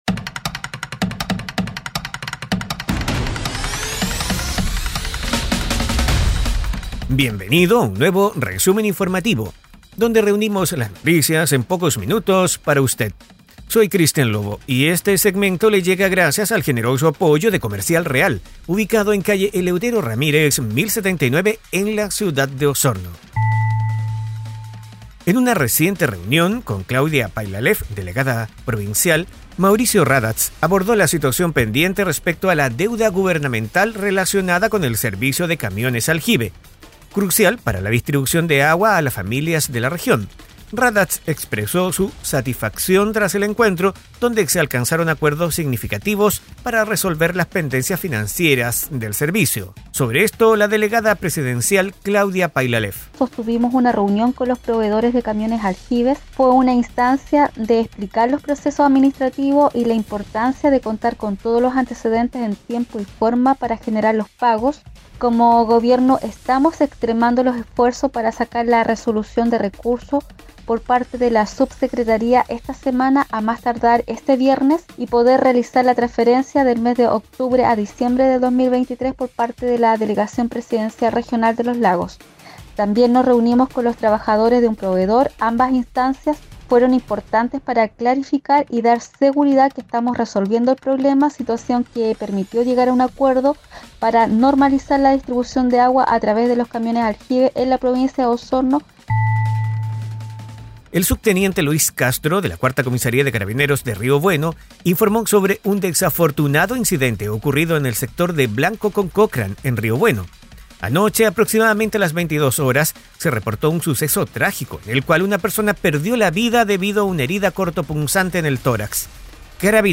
Resumen Informativo 🎙 Podcast 13 de marzo de 2024